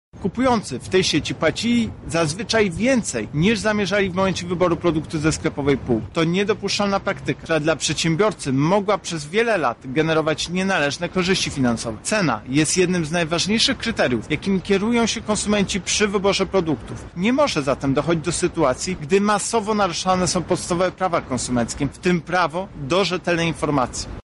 Właściciel sieci sklepów Biedronka przez kilka lat naruszał prawa konsumentów — mówi prezes UOKiK Tomasz Chróstny